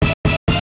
jingles-hit_03.ogg